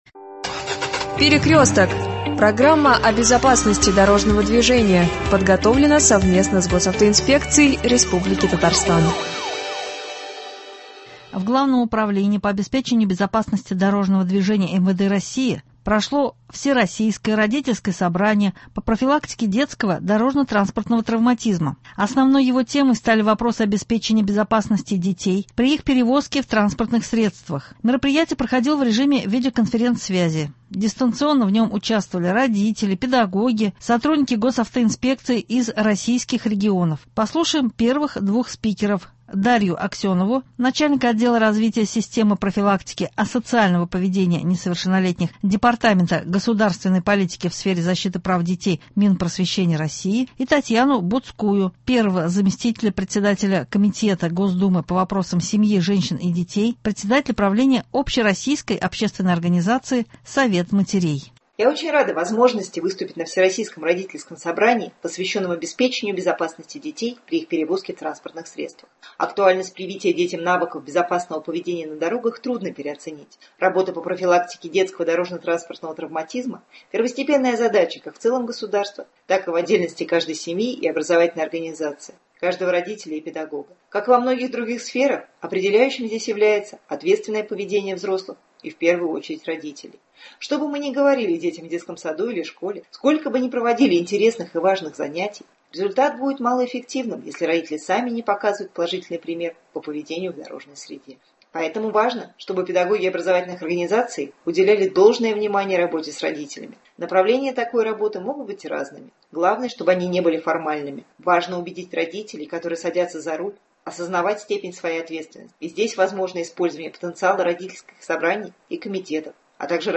В Главном управлении по обеспечению безопасности дорожного движения МВД России состоялось Всероссийское родительское собрание по профилактике детского дорожно-транспортного травматизма. Основной его темой стали вопросы обеспечения безопасности детей при их перевозке в транспортных средствах. Мероприятие проходило в режиме видеоконференцсвязи: дистанционно в нем участвовали родители, педагоги, а также сотрудники Госавтоинспекции из всех российских регионов.